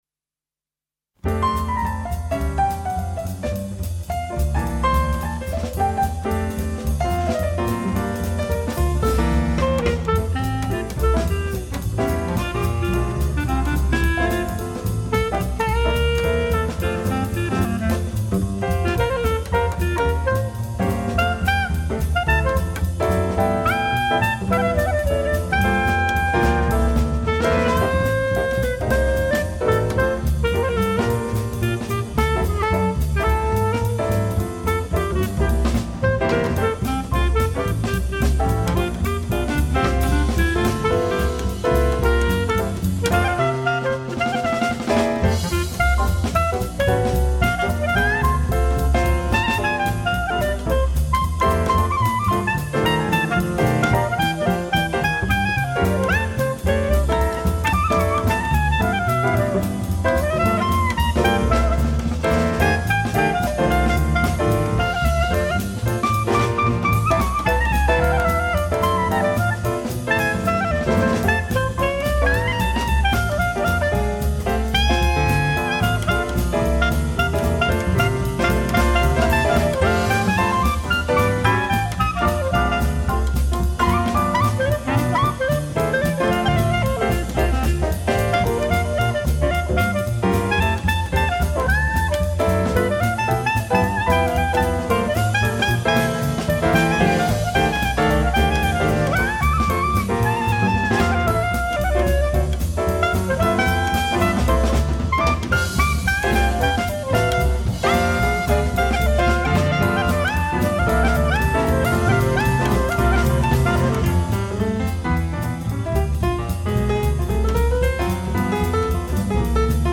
of popular Jazz Clarinet players.
clarinet
trumpet
guitar
bass